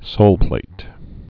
(sōlplāt)